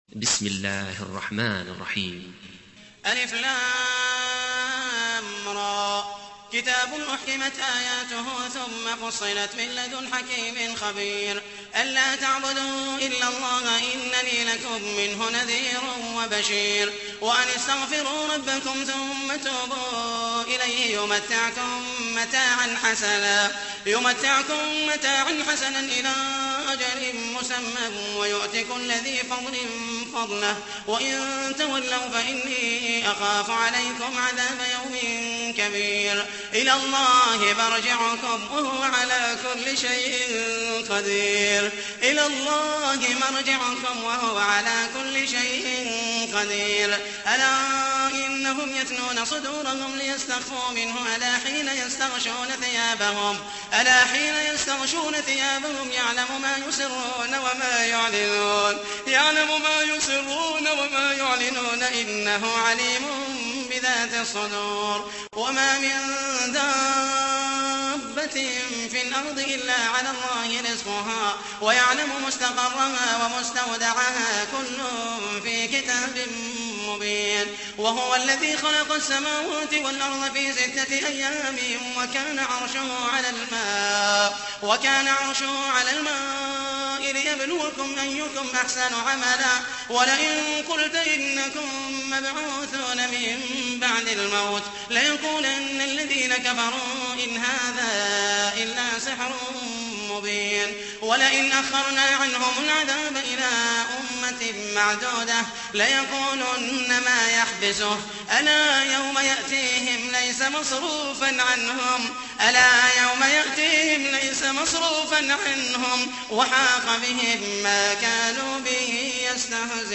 تحميل : 11. سورة هود / القارئ محمد المحيسني / القرآن الكريم / موقع يا حسين